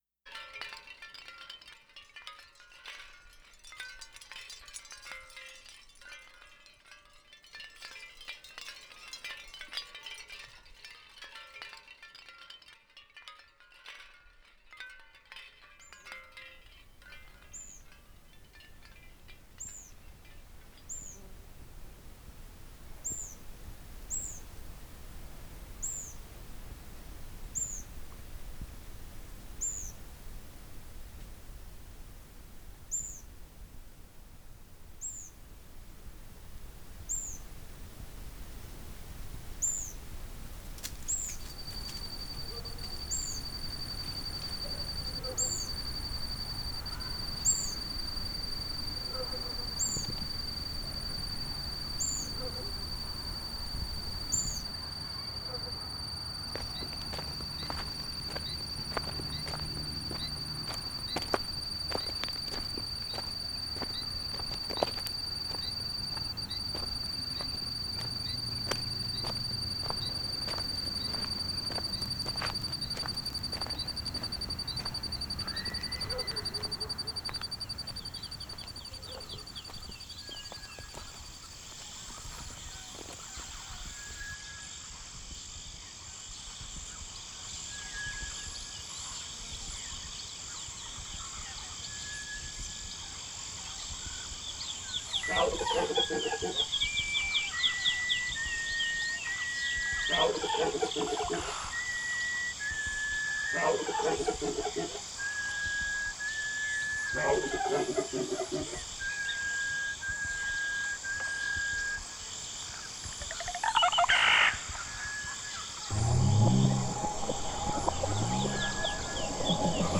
This is a slow-moving, indeed dreamlike piece.